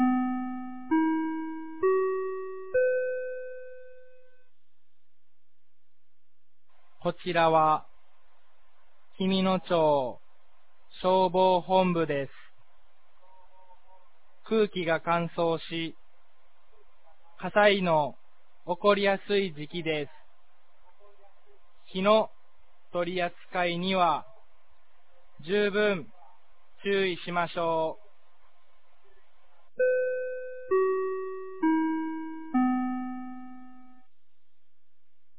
2022年11月12日 16時00分に、紀美野町より全地区へ放送がありました。